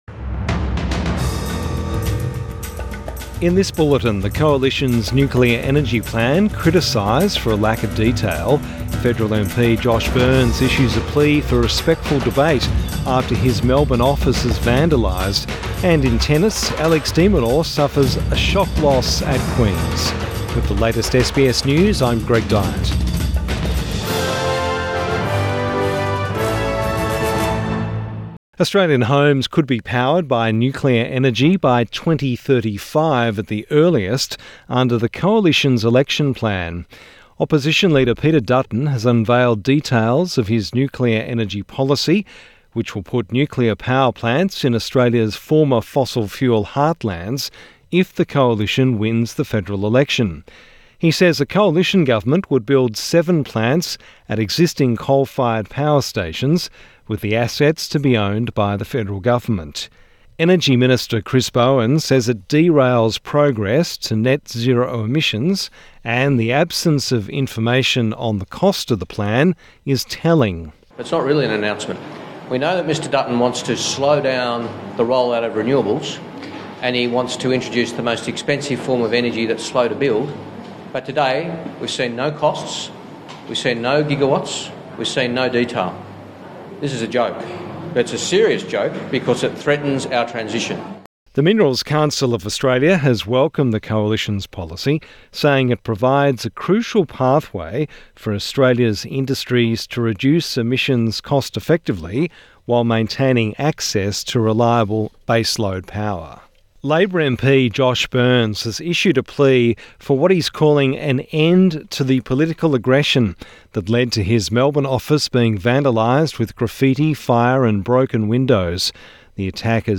Evening News Bulletin 19 June 2024